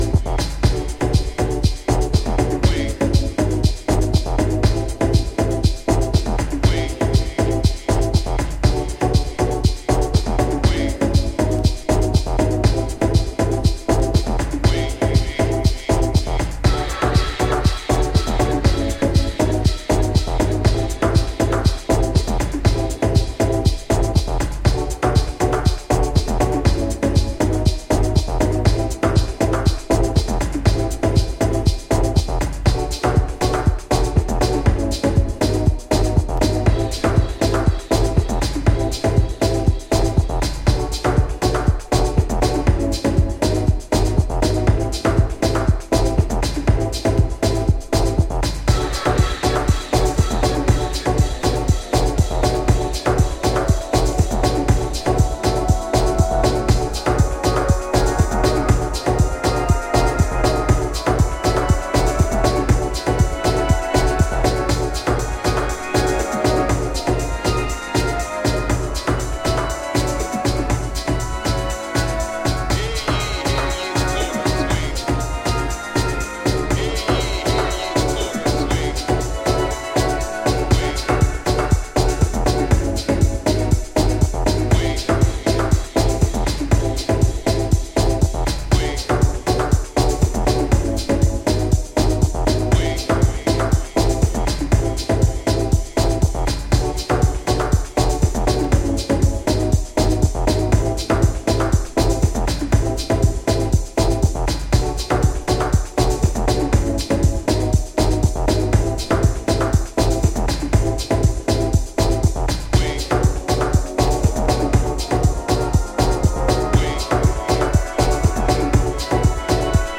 図太いボトムと90'sハウスっぽいシンセのリフレインで展開する完全フロア向けディープ・トラック